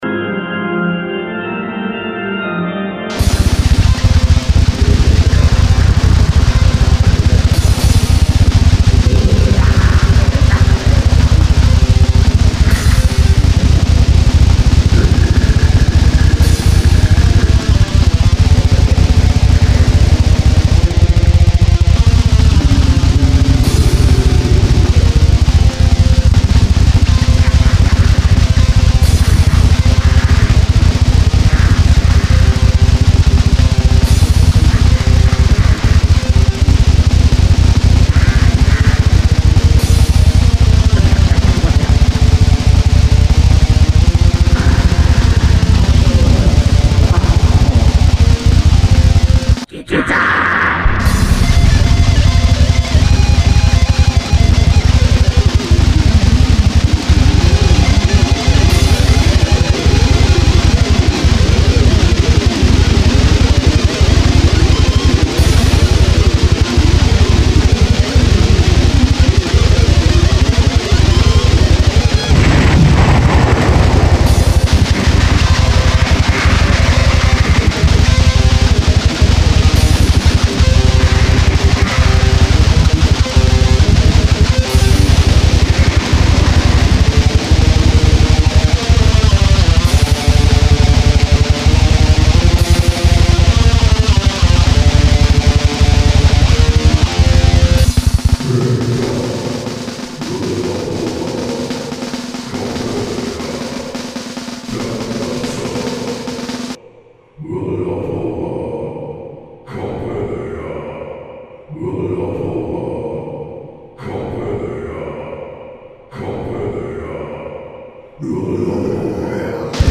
lead vocals
organ and backup vocals
lead guitar
rhythm guitar
bass guitar
drums